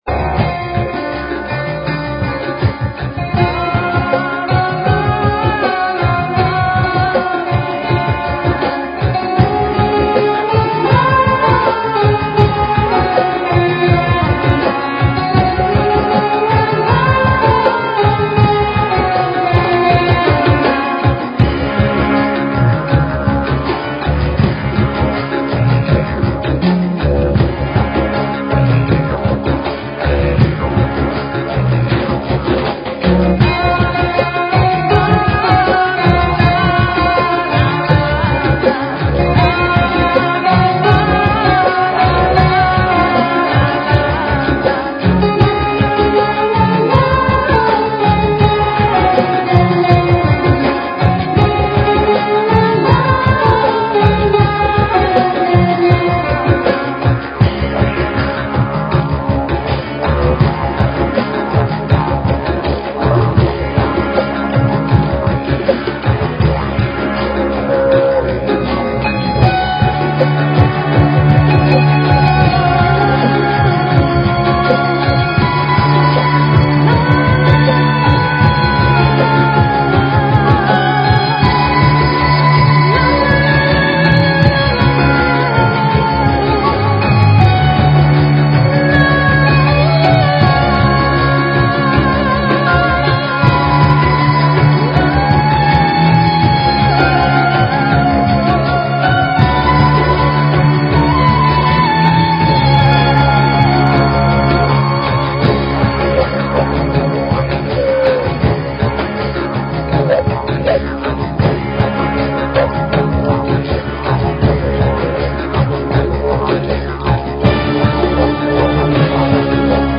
Talk Show Episode, Audio Podcast, Sovereign_Mind_Radio and Courtesy of BBS Radio on , show guests , about , categorized as